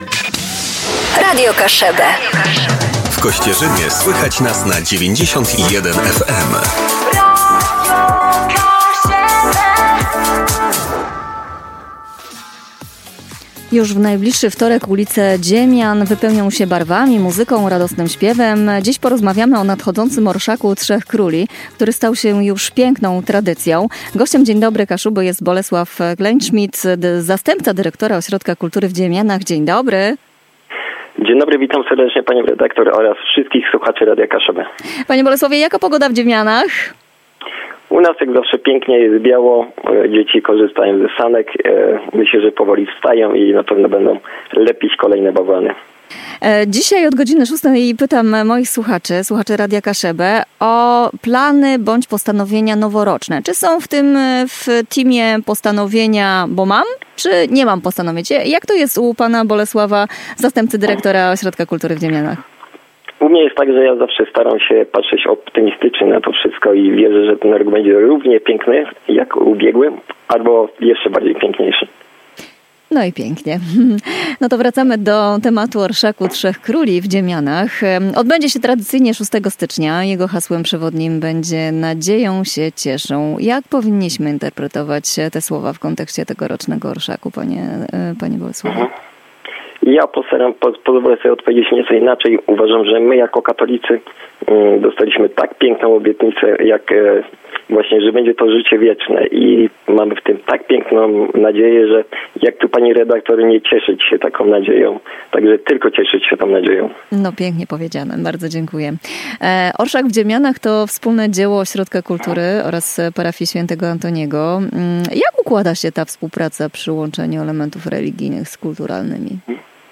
Wywiad był również okazją do podsumowania minionego roku, który dla kultury w Dziemianach był przełomowy.